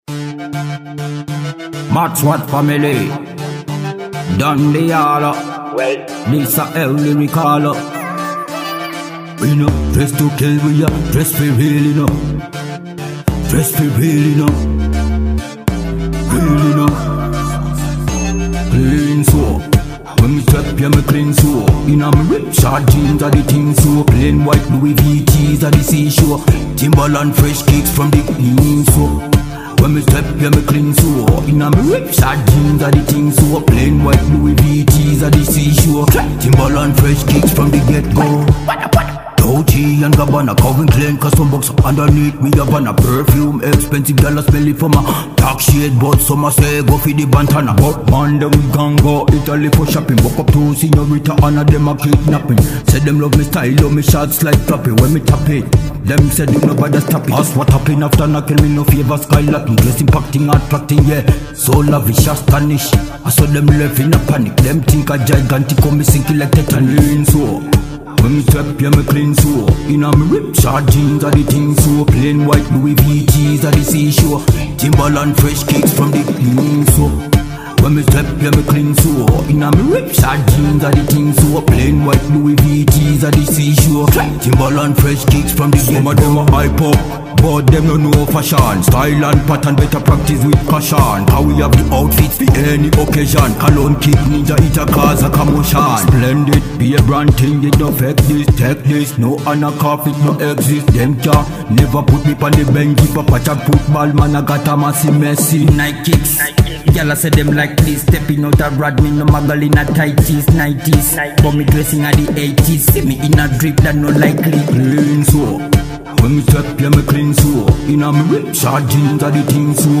• Style: Dancehall